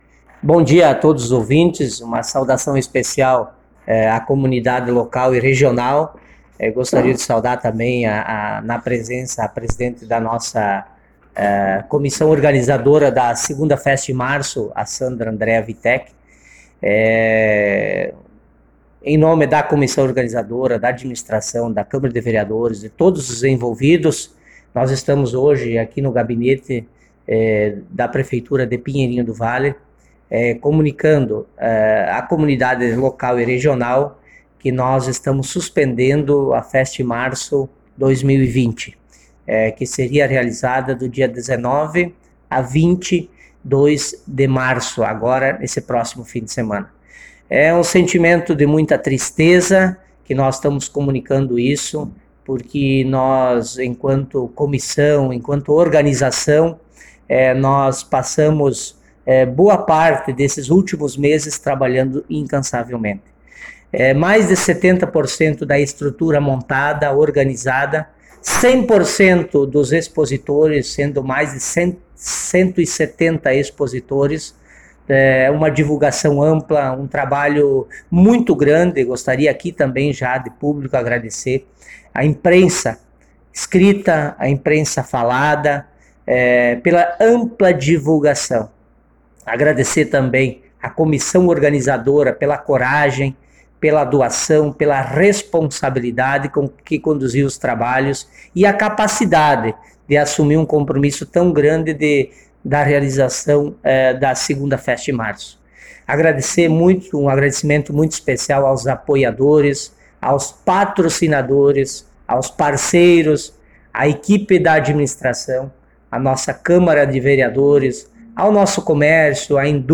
A suspensão da feira, bem como outras medidas que serão adotadas, vão ao encontro das determinações dos órgãos competentes, devido a pandemia do coronavírus. A seguir ouça a entrevista concedida na manhã de hoje para a imprensa local e regional.
Prefeito-Elton-Tatto.mp3